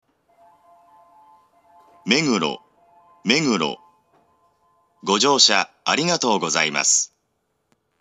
２番線到着放送
meguro2bansen-totyaku4.mp3